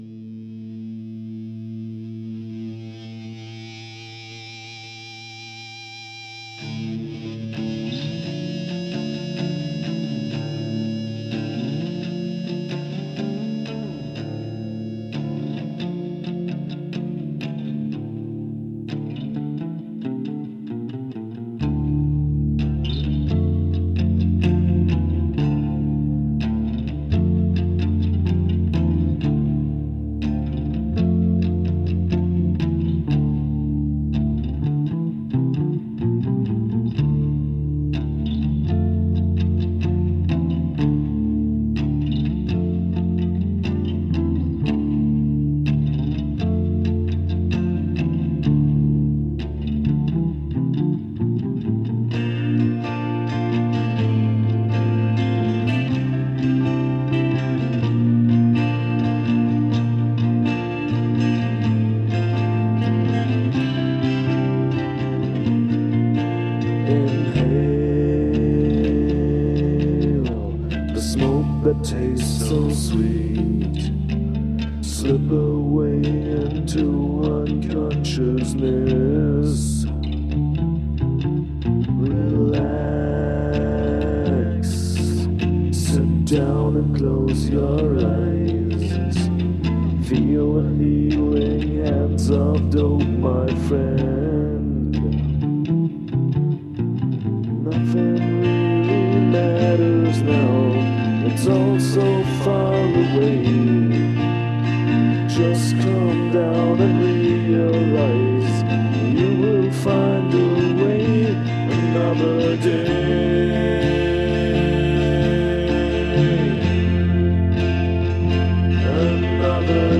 24-Spur/Studio